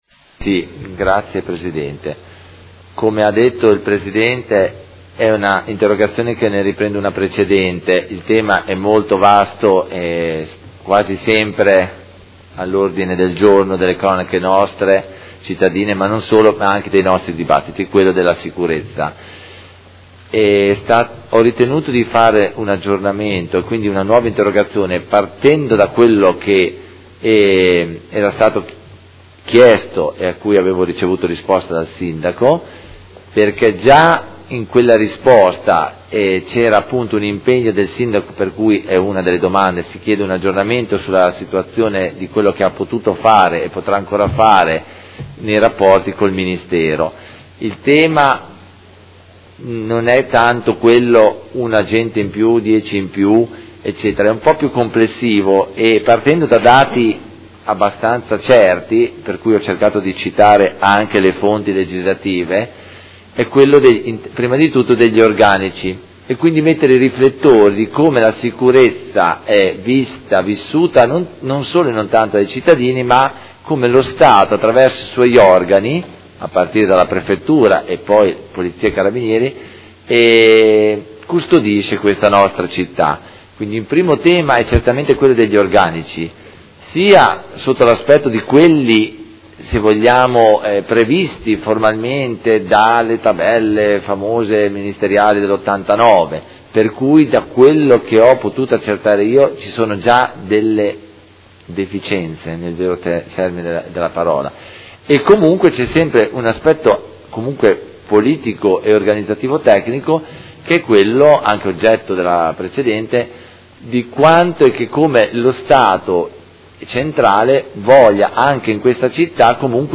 Antonio Carpentieri — Sito Audio Consiglio Comunale
Seduta del 31/03/2016. Interrogazione del Consigliere Carpentieri (P.D.) avente per oggetto: Sicurezza in città: aggiornamenti ed azioni concrete